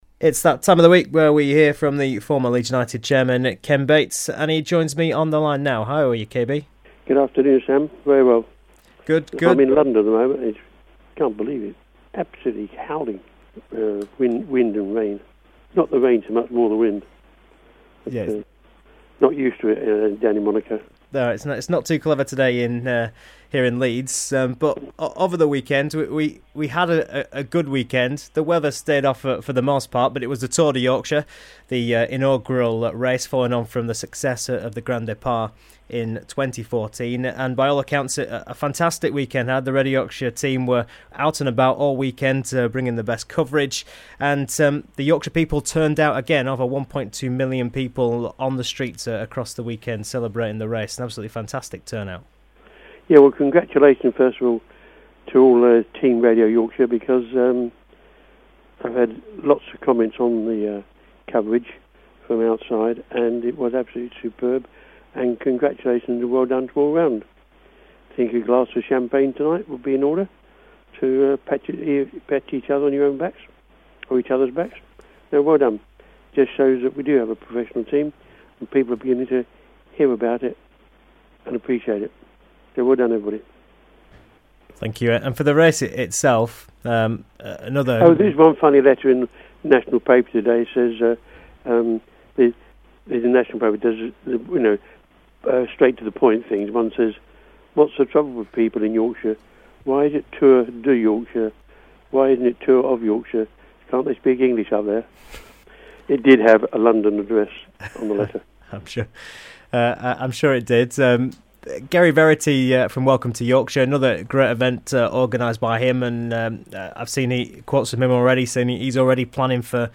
In this weeks interview with the Former Leeds United Chairman Ken Bates he discusses Massimo Cellino's return from suspension. He pays tribute to Jimmy Greaves who is recovering following a severe stroke. Also discussed is the Tour de Yorkshire and election issues.